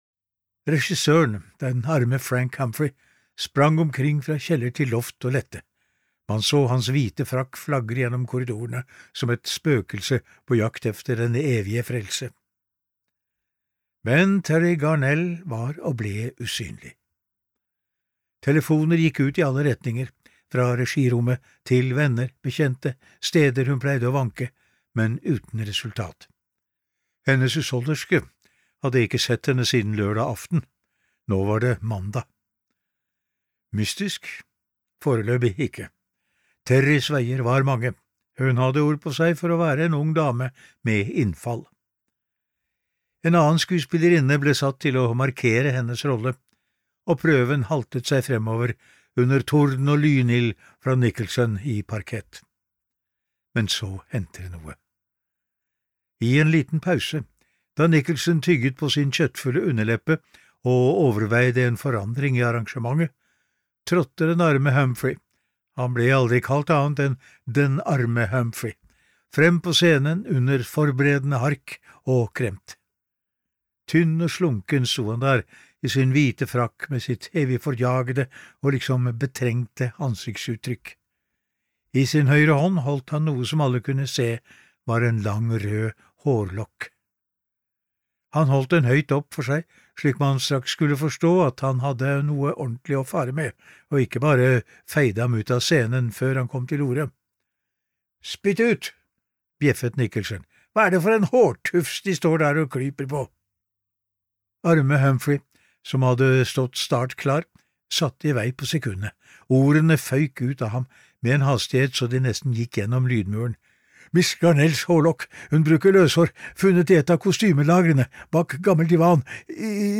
Sist kommer morderen (lydbok) av Edith Ranum